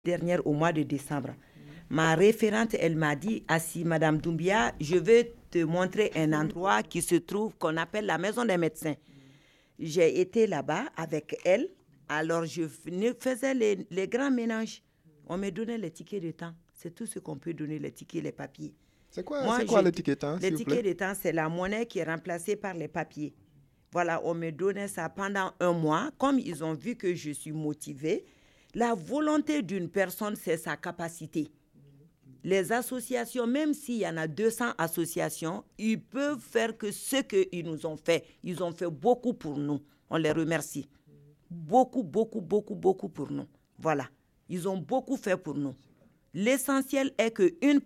Propos enregistrés dans le cadre de l’atelier radio A plus d’une voix.